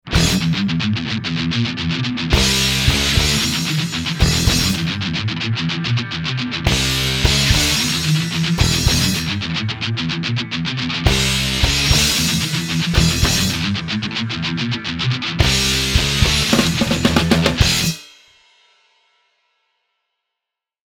Backwards metal.